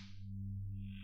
Electric hum.wav